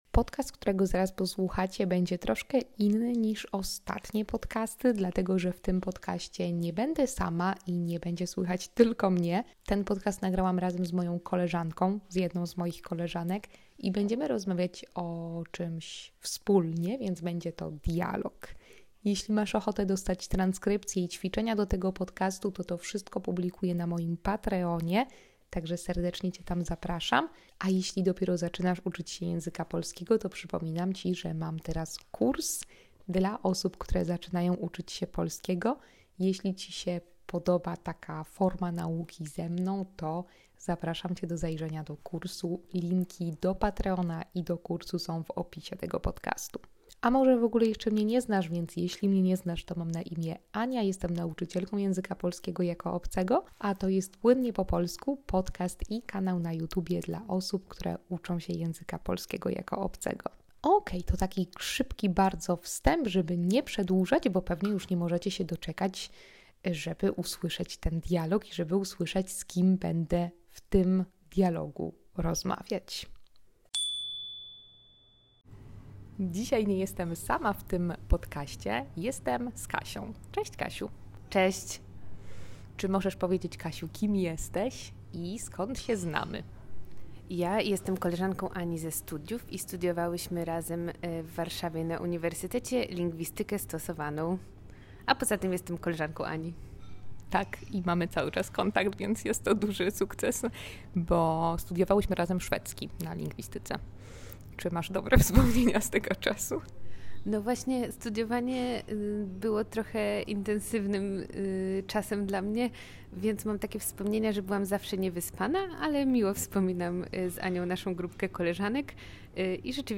#74 Harcerstwo – rozmowa